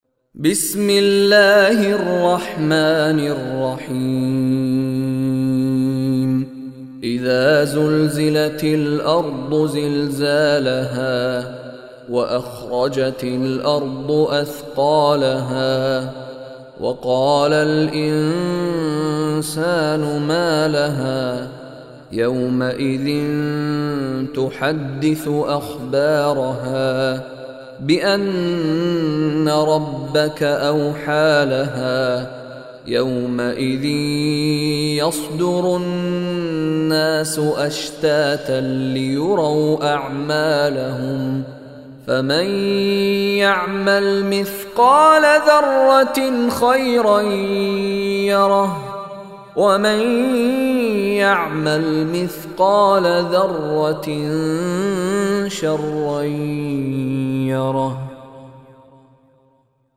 Surah Zalzalah Online Recitation by Mishary Rashid
Surah Al-Zalzalah is 99 chapter of Holy Quran. Listen online and download mp3 tilawat recitation of Surah Al-Zalzalah in the beautiful voice of Sheikh Mishary Rashid Alafasy.